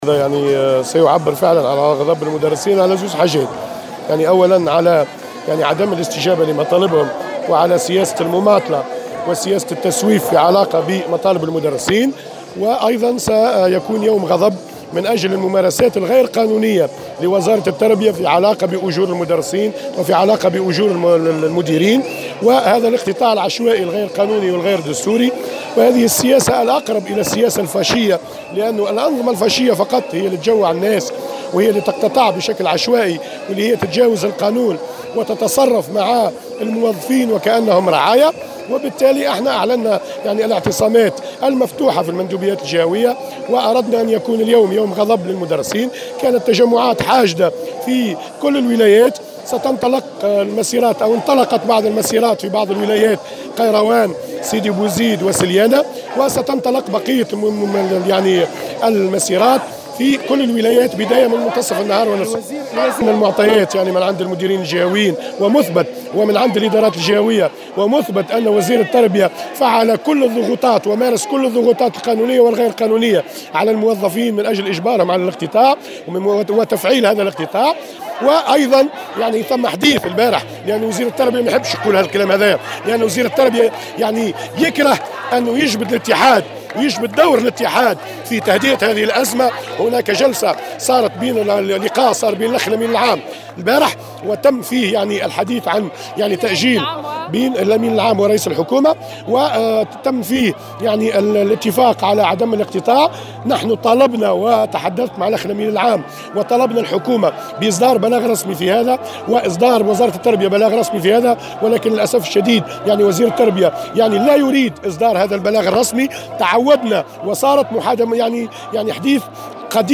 وقال في تصريح لمراسل "الجوهرة أف أم" على هامش مسيرة بشارع الحبيب بورقيبة إن هذ المسيرة تعبر عن غضب المدرسين على عدم الاستجابة لمطالبهم وبسبب الممارسات غير القانونية من خلال الاقتطاع العشوائي مشبها هذه الممارسات بالأنظمة الفاشية، وفق تعبيره.